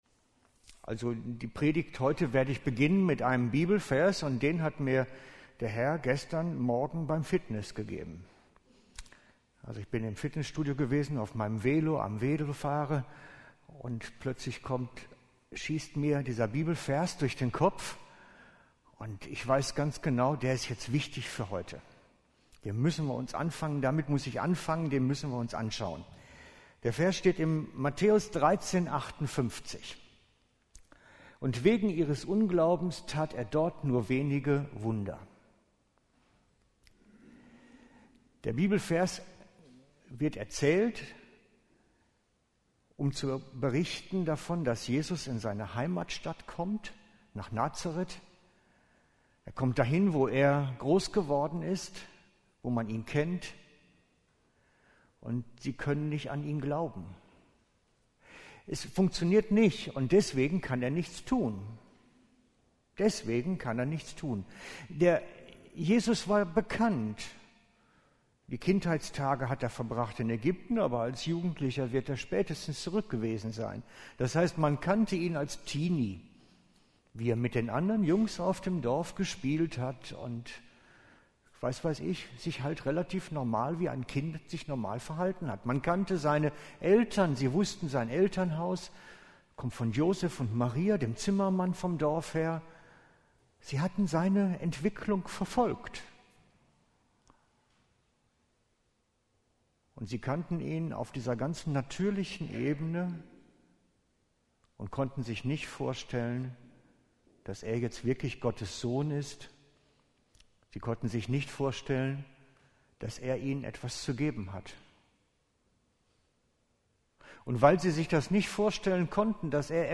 Durch den heiligen Geist Jesus erfahren Passage: Matthäus 13:58 / 2.Korinther 3:18 Dienstart: Sonntag Morgen « sein Reden hören